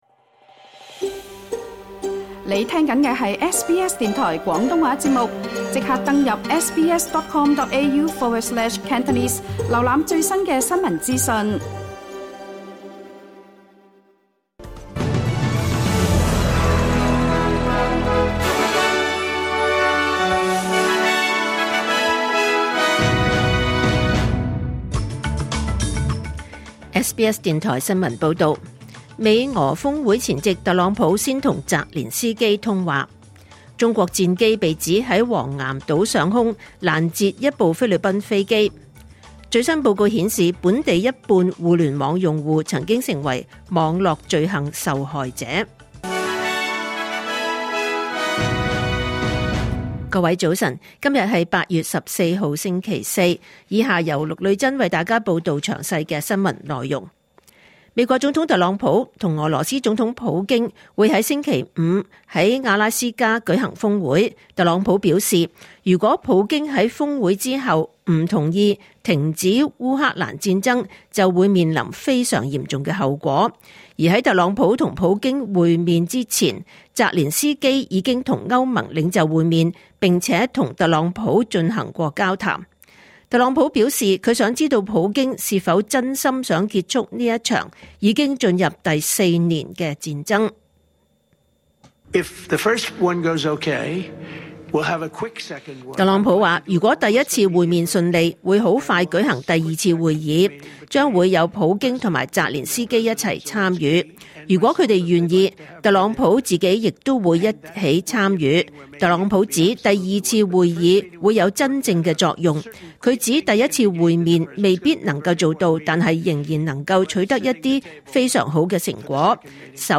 2025年8月14日SBS廣東話節目九點半新聞報道。